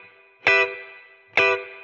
DD_TeleChop_130-Emin.wav